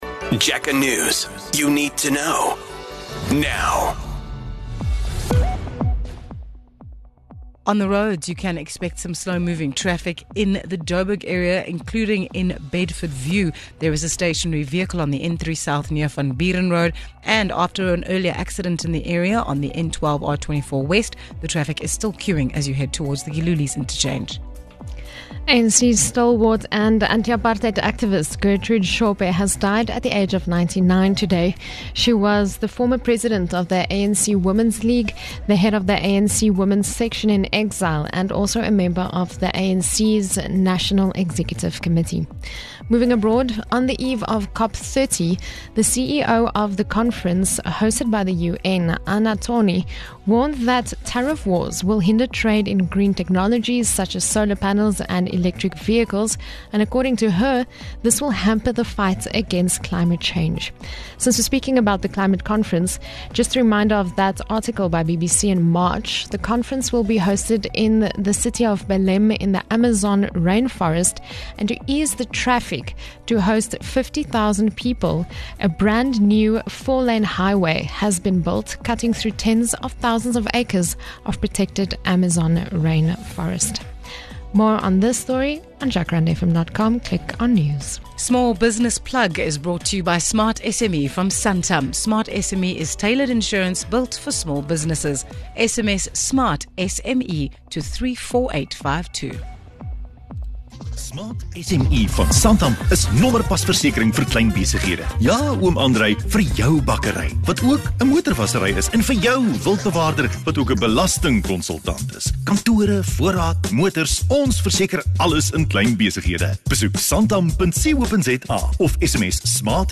Jacaranda FM News Bulletins
The Jacaranda FM News team is based in Gauteng – but covers local and international news of the day, providing the latest developments online and on-air. News bulletins run from 5am to 7pm weekdays, and from 7am to 6pm on weekends and public holidays.